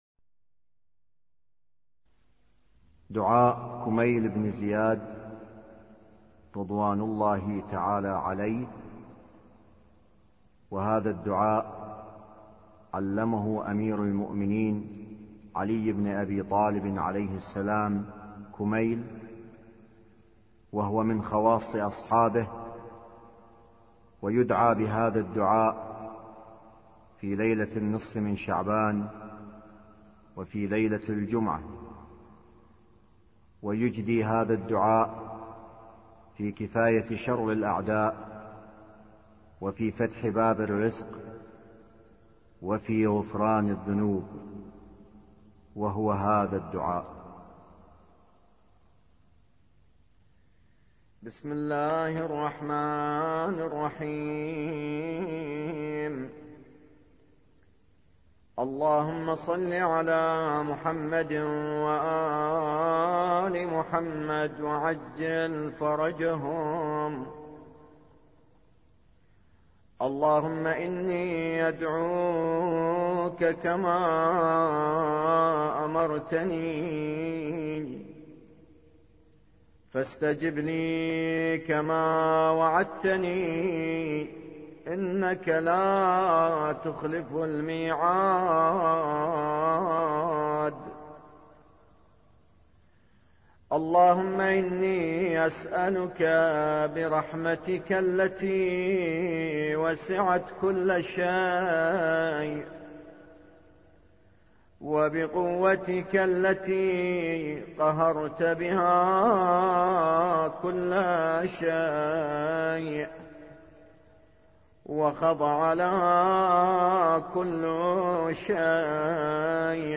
خطبة له عليه السلام المعروفة بالديباج وفيها وصايا شتى